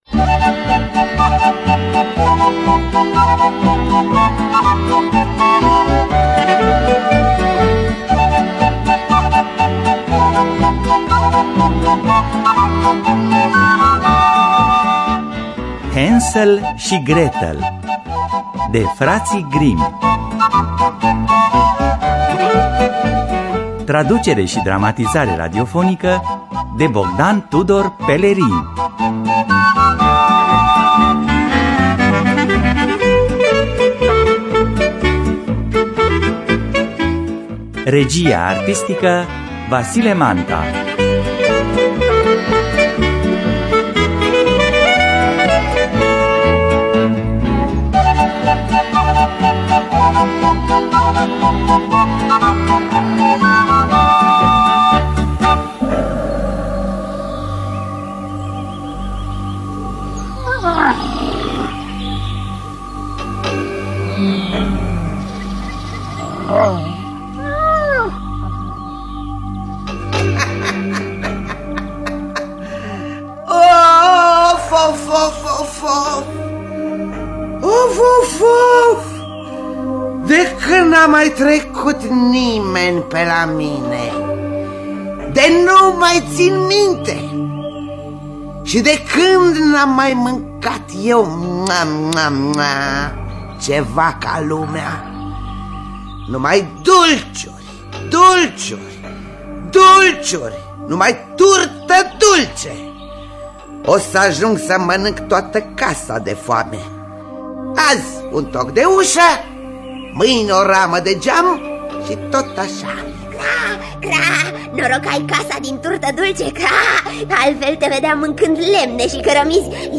Hansel şi Gretel de Fraţii Grimm – Teatru Radiofonic Online